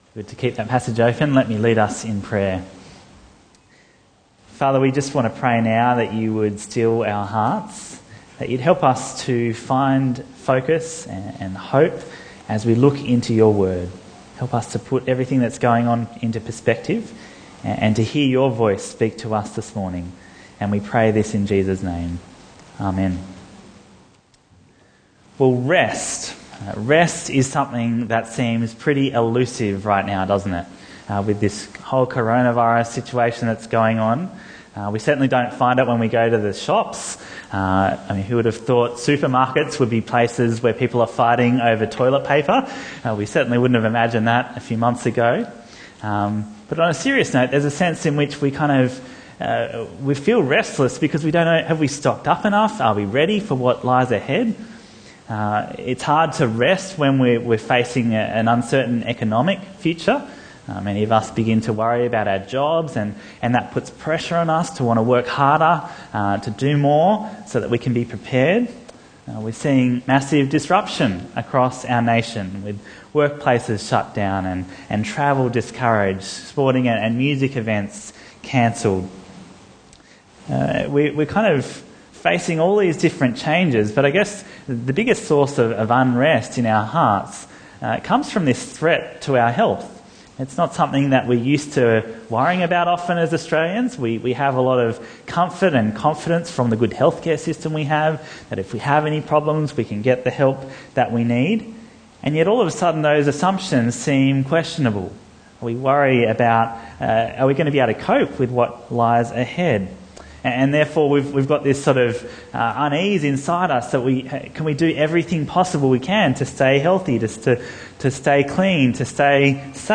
Bible Talks